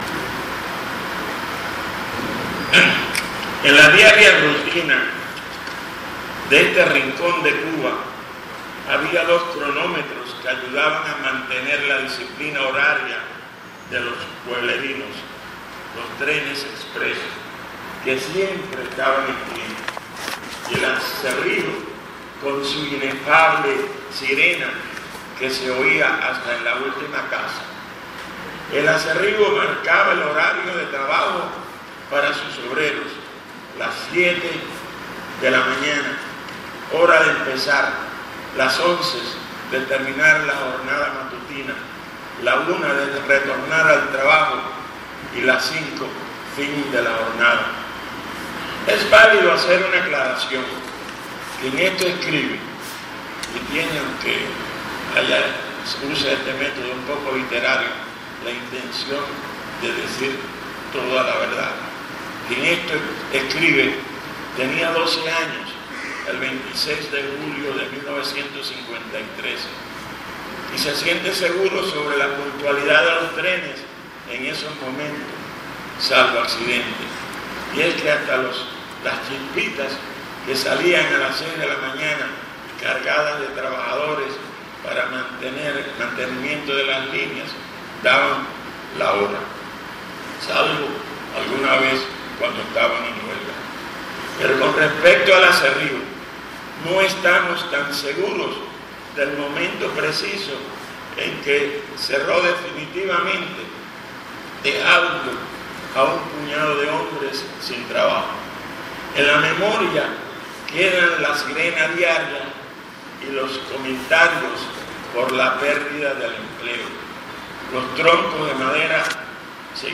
panelista-2.mp3